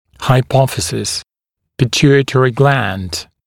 [haɪ’pɔfəsɪs] [pɪ’tjuːɪtrɪ glænd][хай’пофэсис] [пи’тйу:итри глэнд]гипофиз